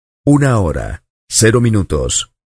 Grabación sonora de locutor retransmitiendo el siguiente comentario: "Una hora, cero minutos"
hombre
locutor
Sonidos: Voz humana